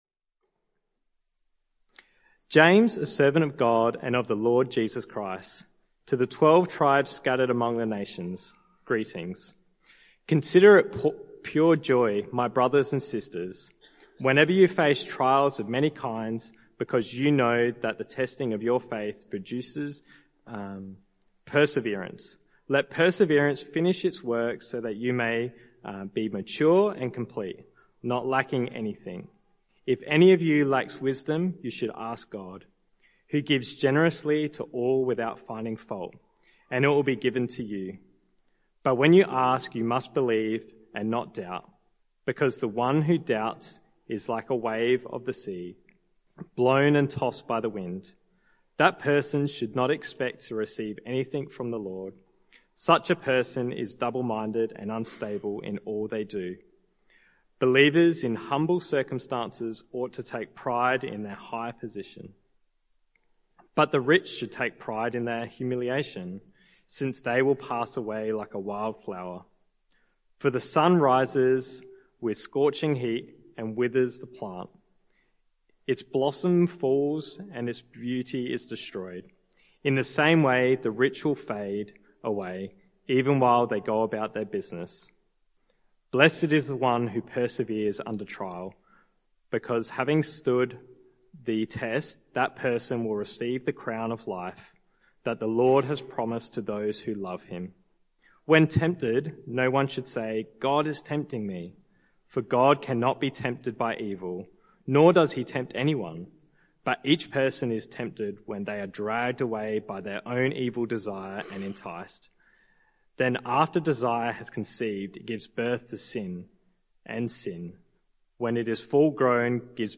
Talk Summary